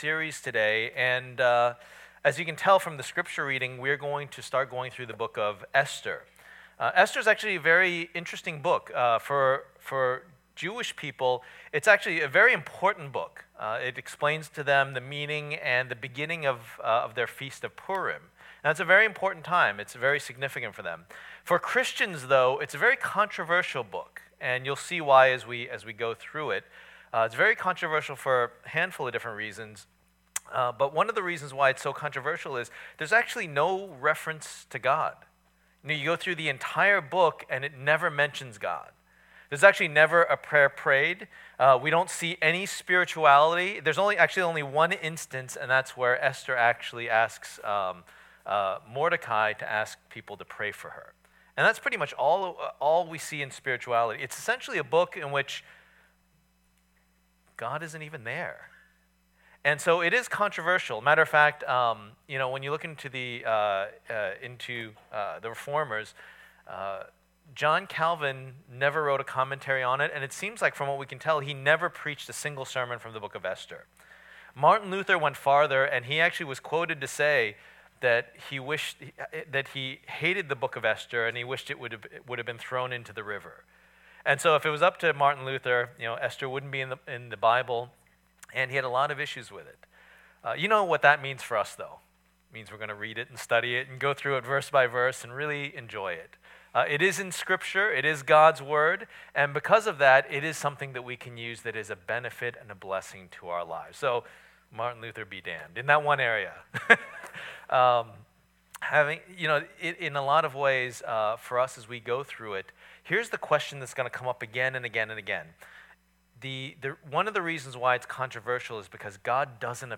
2016 The King and His Kingdom Preacher